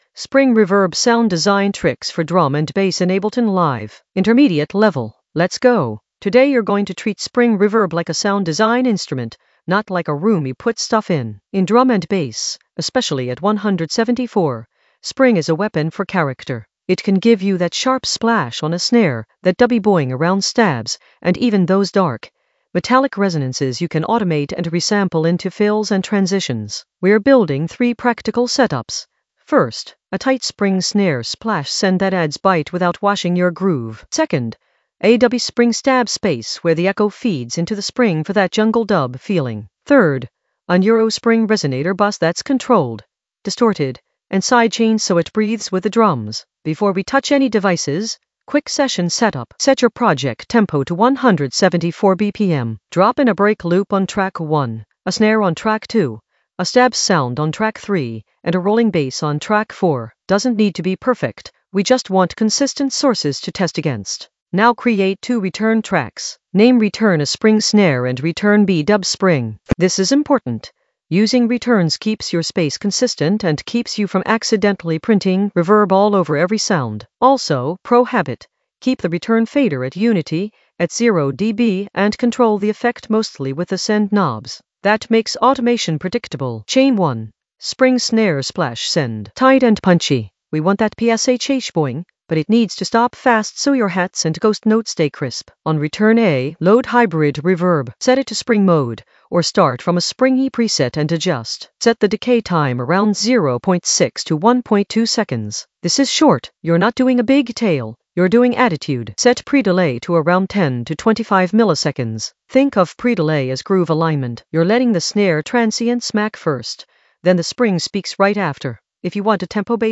Narrated lesson audio
The voice track includes the tutorial plus extra teacher commentary.
An AI-generated intermediate Ableton lesson focused on Spring reverb sound design tricks in the Sound Design area of drum and bass production.